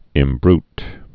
(ĭm-brt)